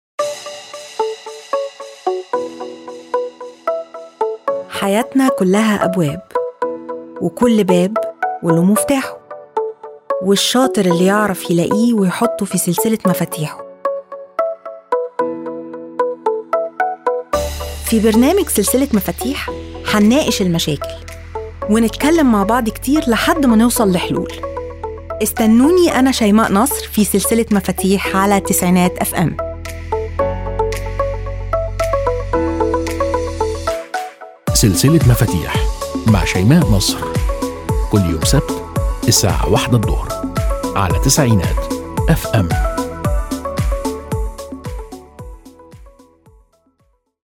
برومو برنامج سلسلة مفاتيح